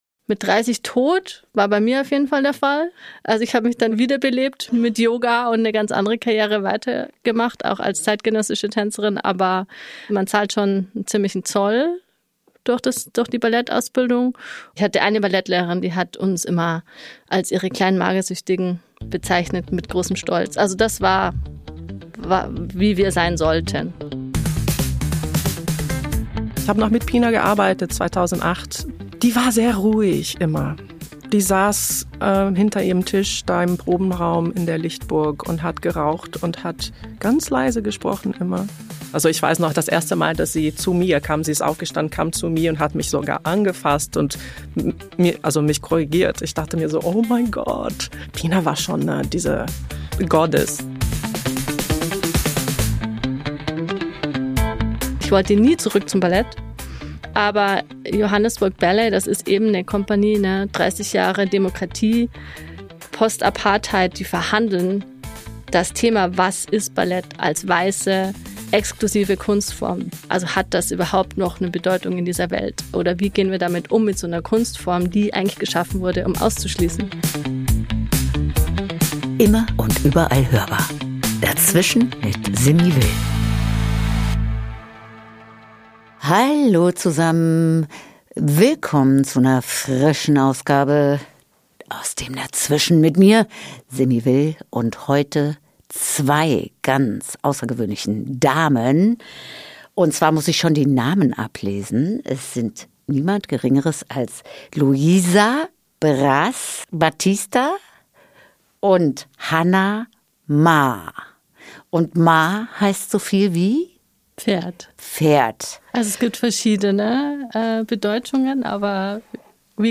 Ein Gespräch über Diversität, körperliche und kulturelle Gewalt, Demokratiearbeit in der Kunst, Trier, Pina Bausch – und über die große Kraft, weiterzugehen.